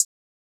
Metro Hi Hat 2.wav